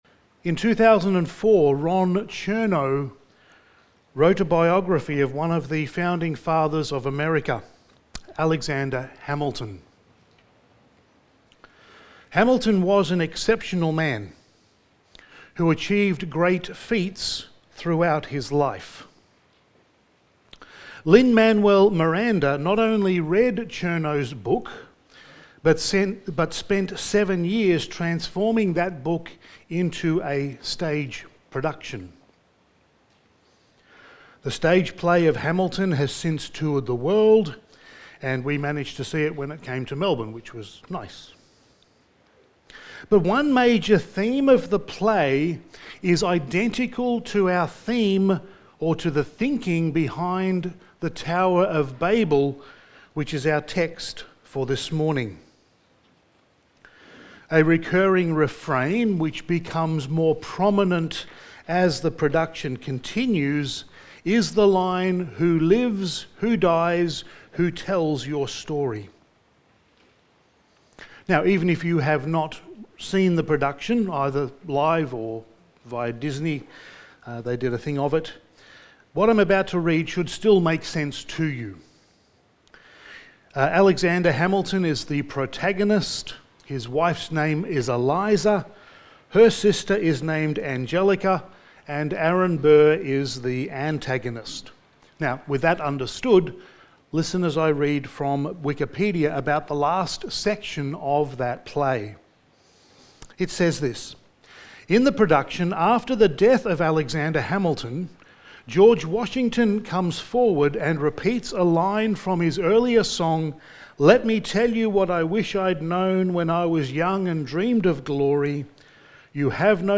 Sermon
Passage: Genesis 11:1-9 Service Type: Sunday Morning